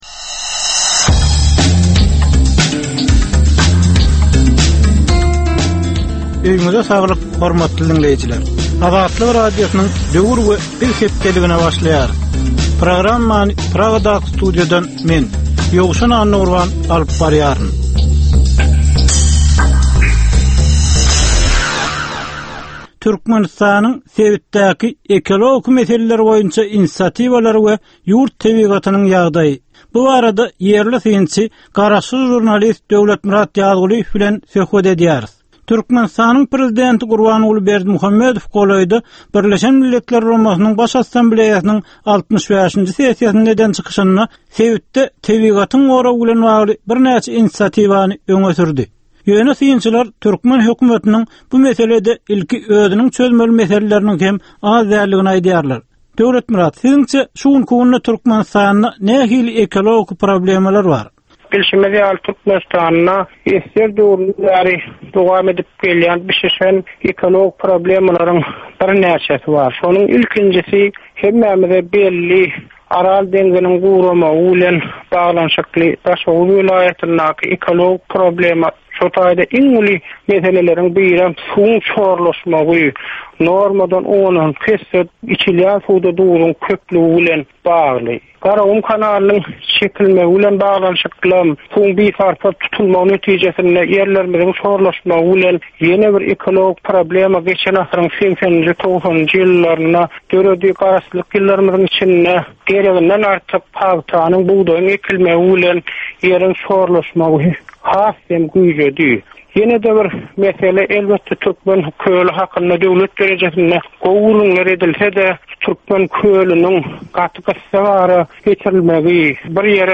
Türkmen jemgyýetindäki döwrüň meseleleri we döwrüň anyk bir meselesi barada ýörite gepleşik. Bu gepleşikde diňleýjiler, synçylar we bilermenler döwrüň anyk bir meselesi barada pikir öwürýärler, öz garaýyşlaryny we tekliplerini orta atýarlar.